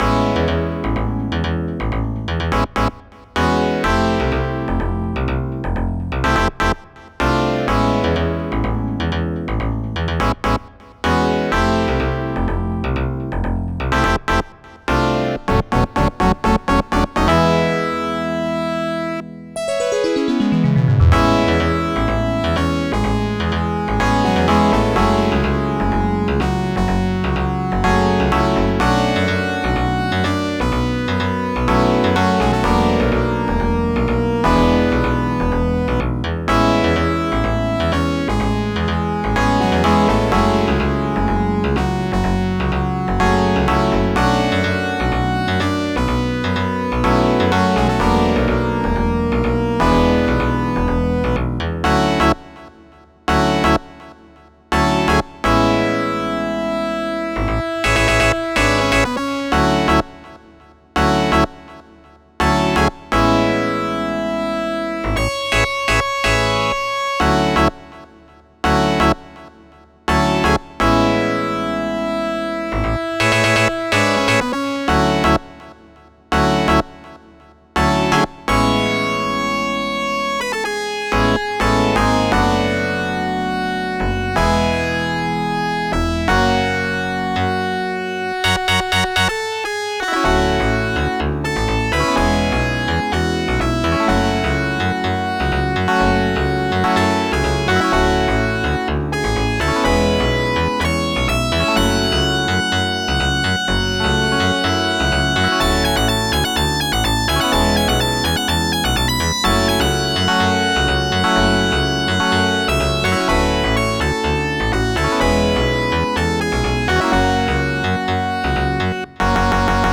I made a script that converts raw YM2151 registers to bank formats like OPM.
They're almost identical. ~There is some distortion in GENNY but that's probably a bug in that plugin (it has been abandoned)~ That's actually 'ladder effect'.